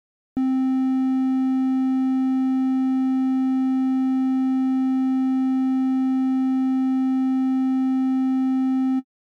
Triangle_C4.mp3